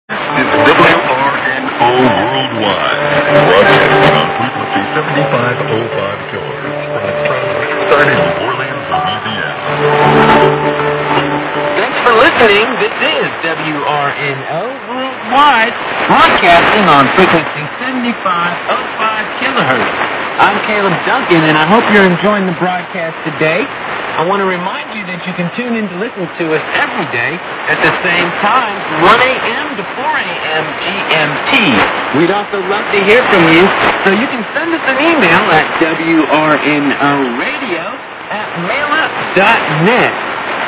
WRNO - 1982, 1985, 1990, 2013 (2), Station ID Audio